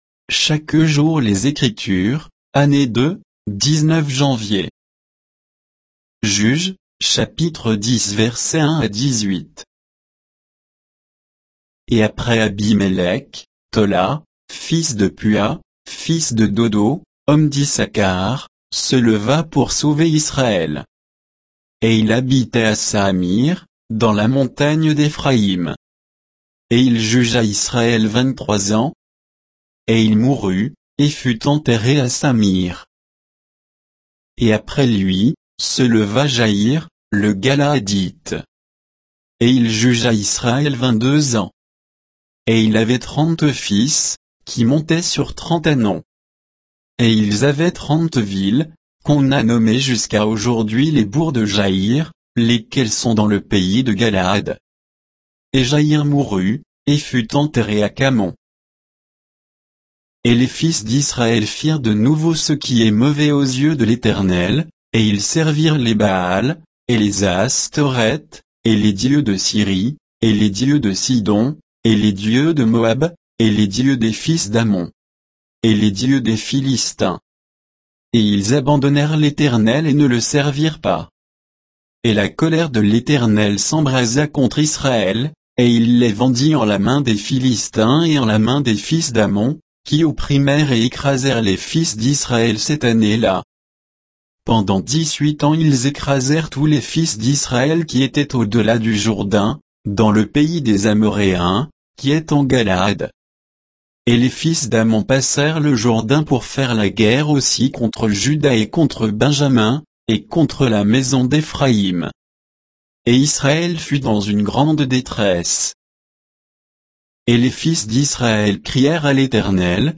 Méditation quoditienne de Chaque jour les Écritures sur Juges 10, 1 à 18